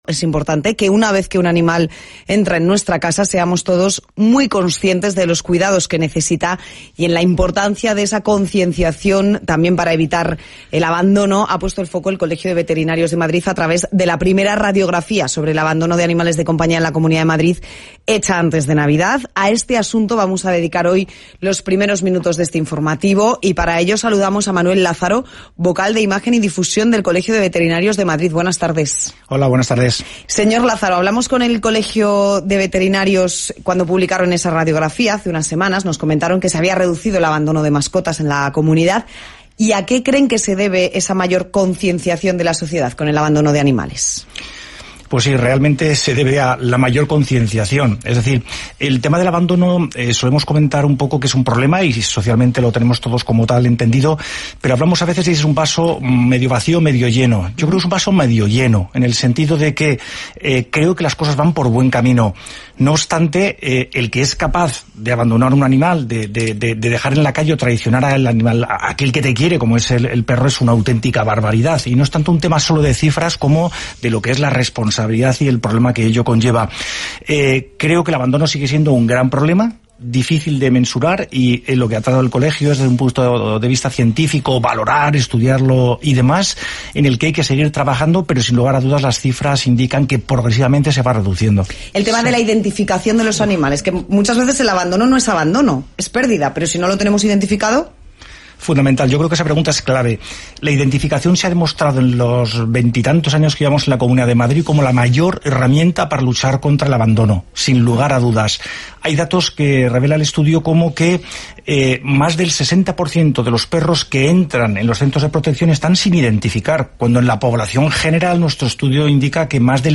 Hay que resaltar la cobertura que ofreció El País, diario líder en España, dedicándole una página completa, TVE recogiéndolo en informativos de máxima audiencia, o Radio Nacional en ‘Crónicas de Madrid’ en un espacio de más 13 minutos